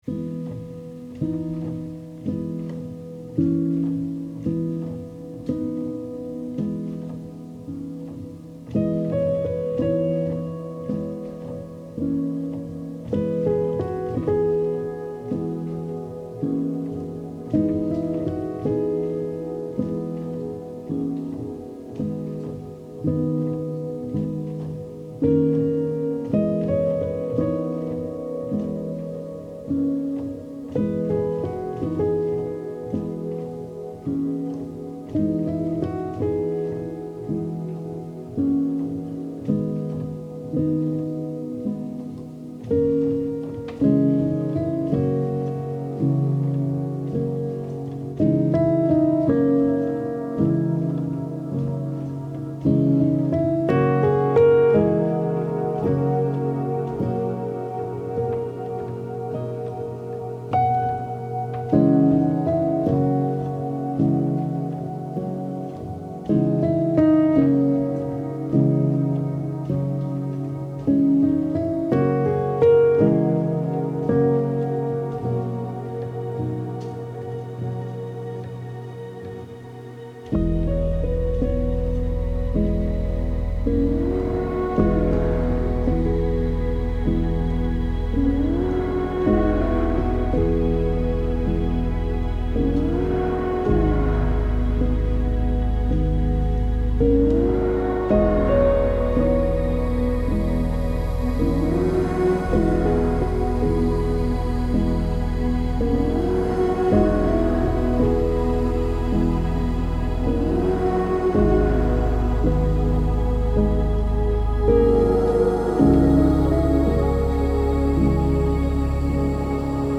Subtle melodies over warm textures.